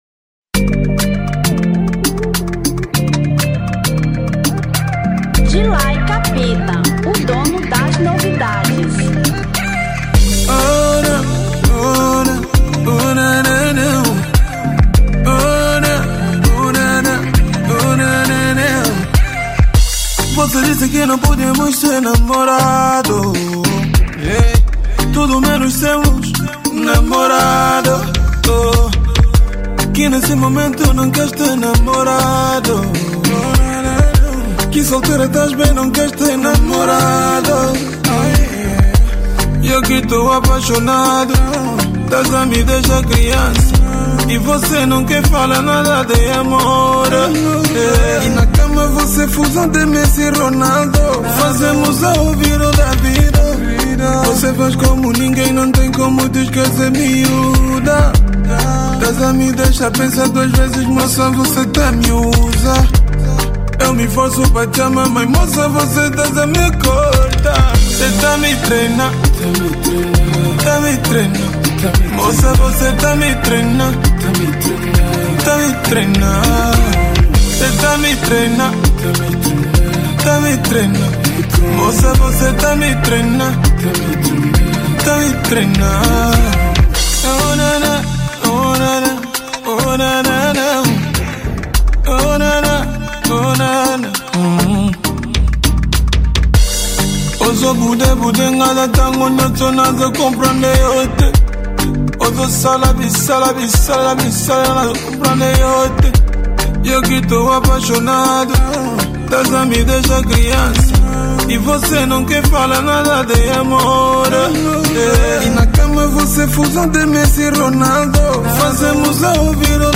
Kizomba 2017